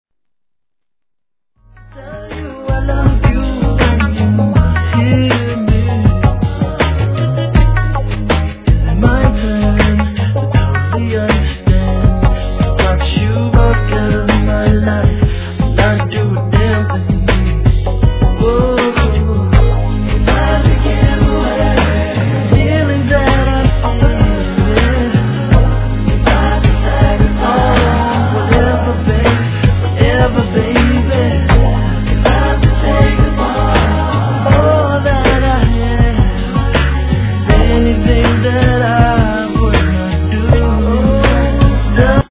HIP HOP/R&B
お得意の美メロとキャッチーなフックがツボ！